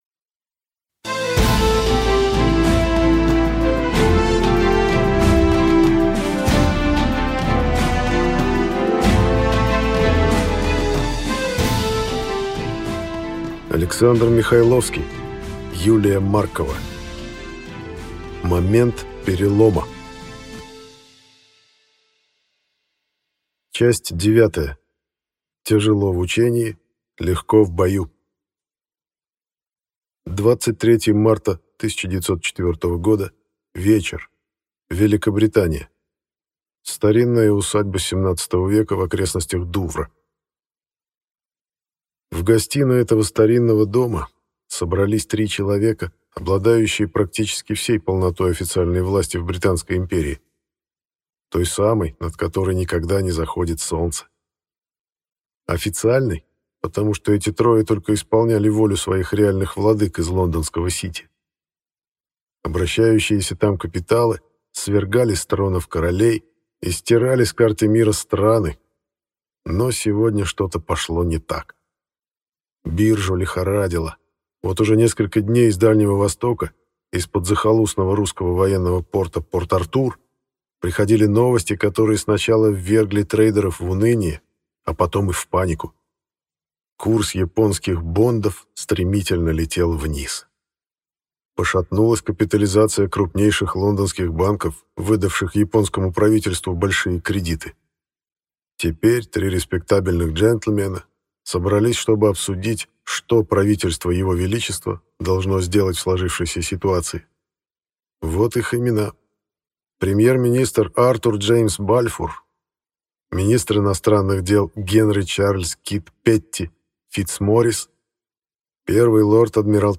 Аудиокнига Момент перелома | Библиотека аудиокниг
Прослушать и бесплатно скачать фрагмент аудиокниги